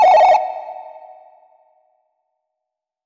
FX - MOTOROLA.wav